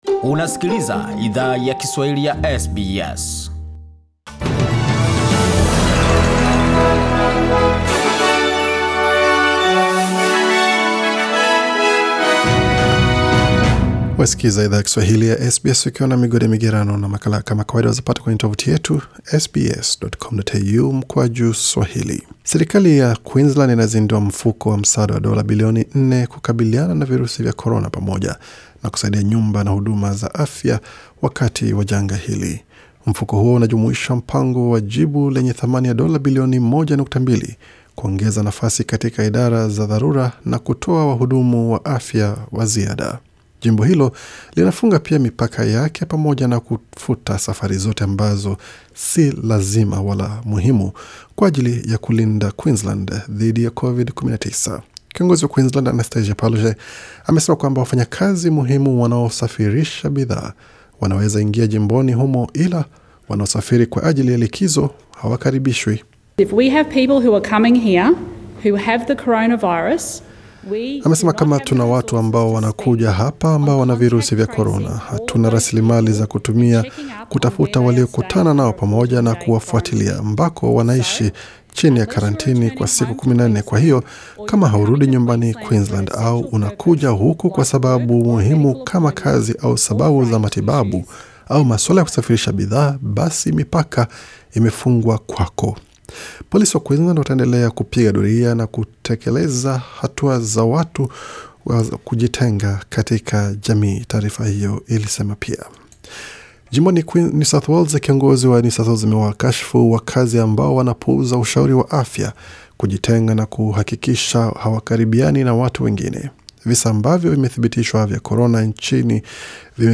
Taarifa za habari: Majimbo yafunga mipaka kukabiliana na usambaaji wa Covid-19